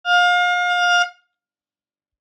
Ukážka zvuku vzduchového klaksóna/fanfáry s ručnou pumpou
• Rozprúďte atmosféru a buďte neprehliadnuteľní s týmto výkonným klaksónom-fanfárou na stlačený vzduch.